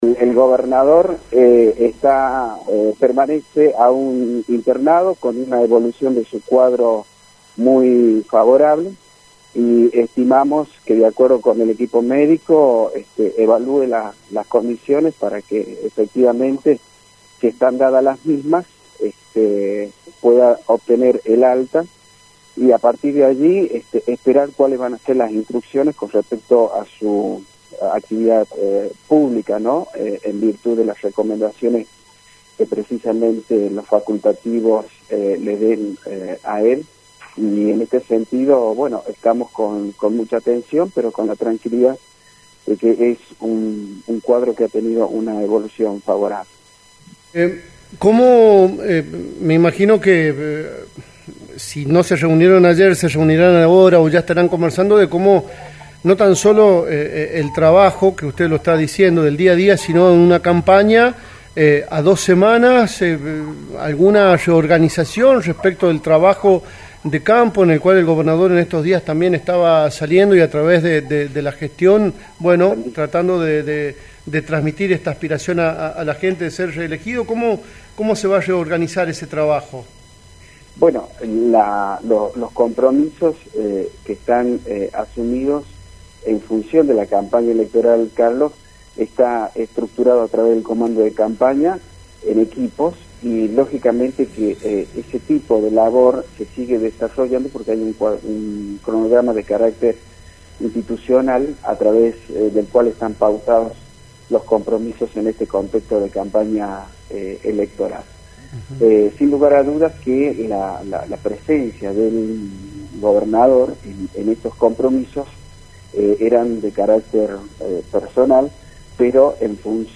Pedro Goyochea, asesor general de Gobierno.
“El Gobernador permanece aún internado con una evolución de su cuadro muy favorable”, indicó el asesor general de Gobierno, Pedro Goyochea, en diálogo con Radio Nacional La Rioja.